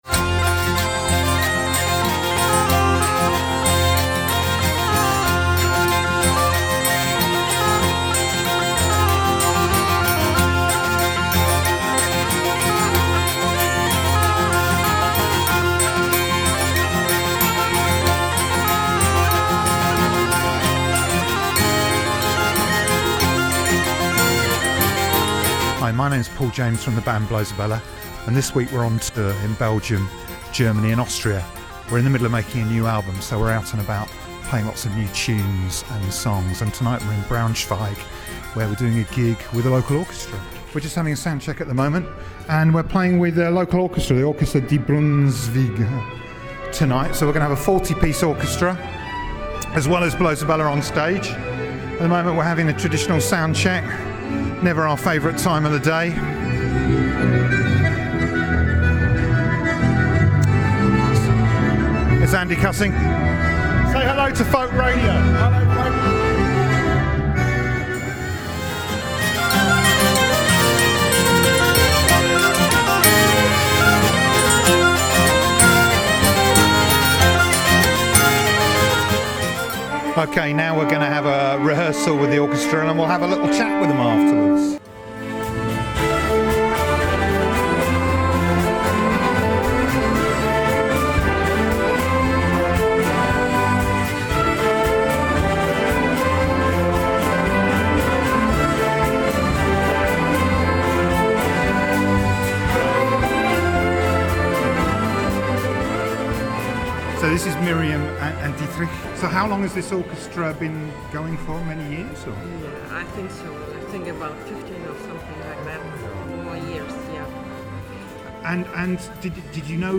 diatonic accordion.
clarinet, saxophones.
bagpipes, saxophones.
hurdy-gurdy.
violin.
bass guitar.